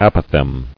[ap·o·phthegm]